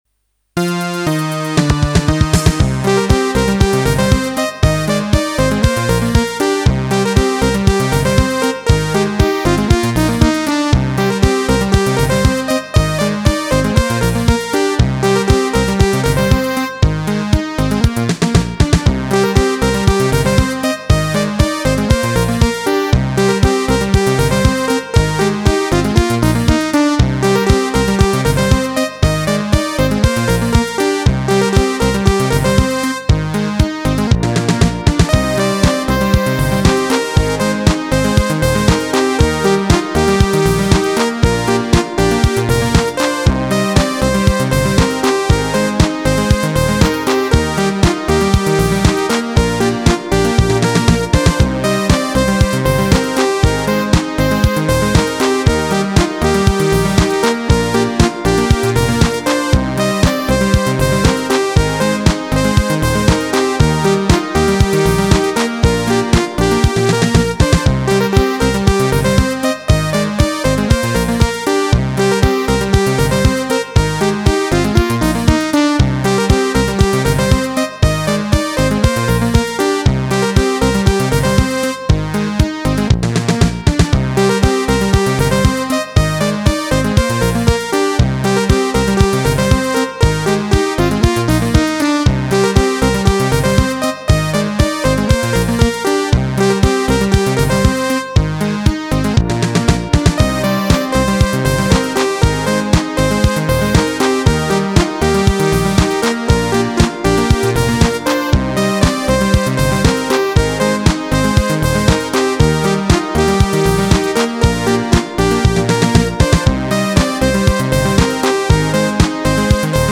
Ik voeg steeds nieuwe ritmedemo's bovenaan toe, scroll dus naar beneden om alles te zien.
030 16 Beat Dance